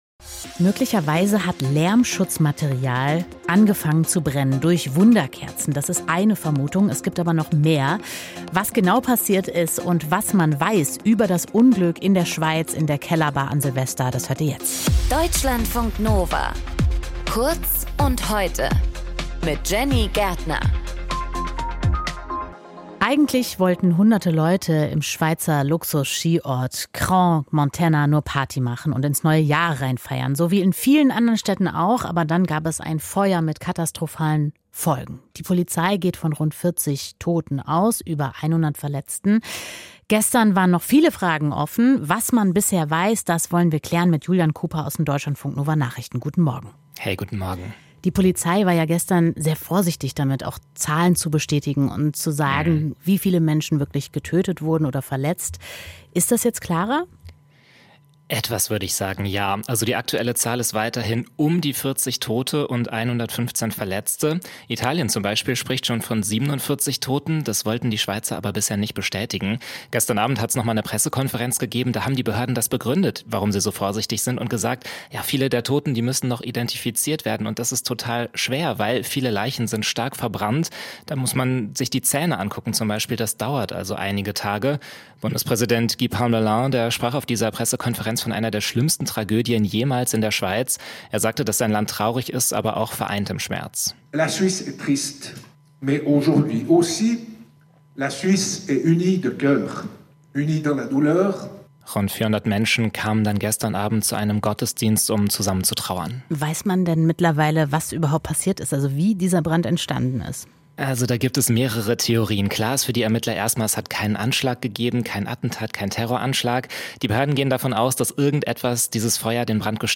Moderation:
Gesprächspartner: